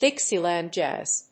アクセントDíxieland jázz